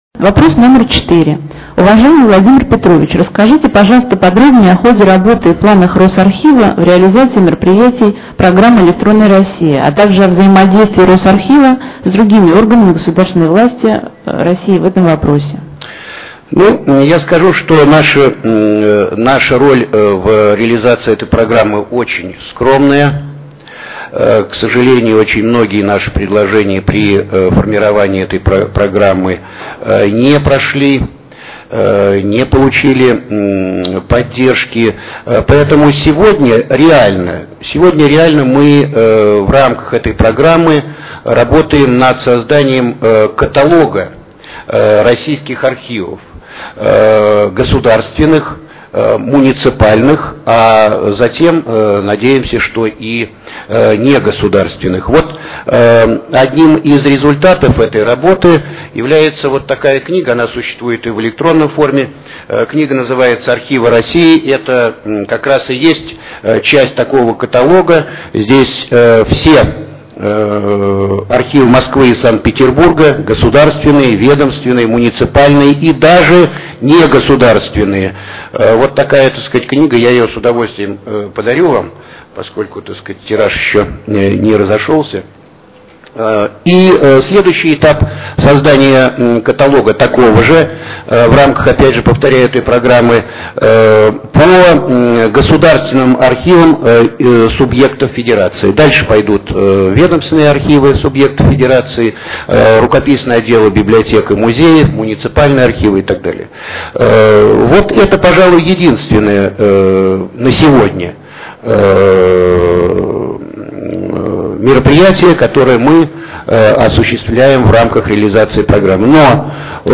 Интернет-конференция